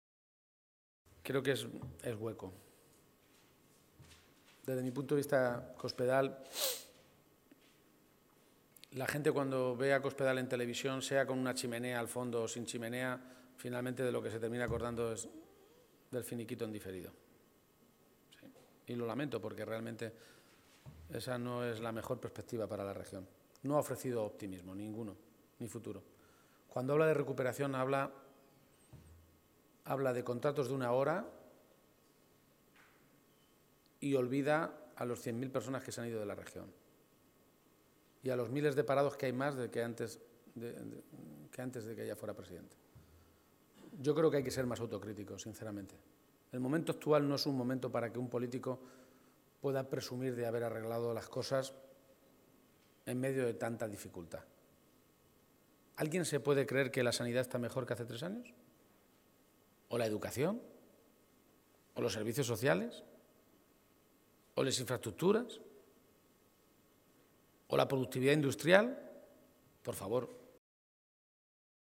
García-Page se pronunciaba de esta manera esta mañana, en una comparecencia, en Toledo, ante los medios de comunicación, en la que hacía balance municipal y regional del año que termina en unos días.
Cortes de audio de la rueda de prensa